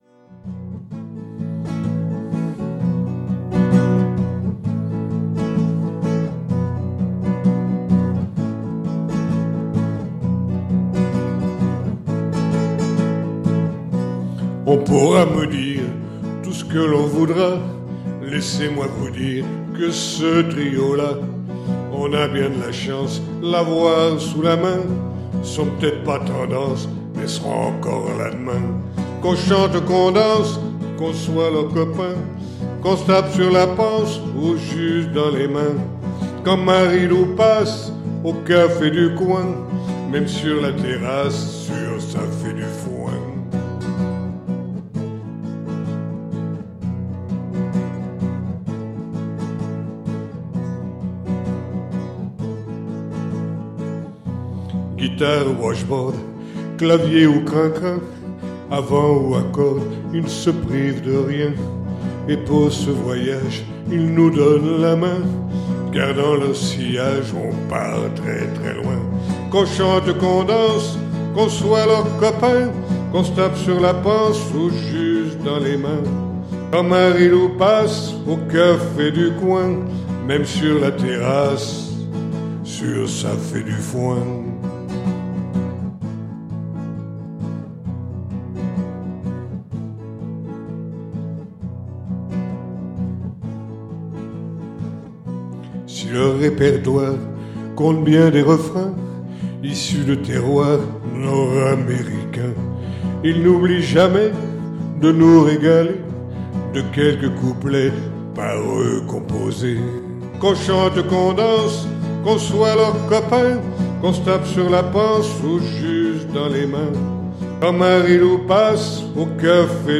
Capo 2°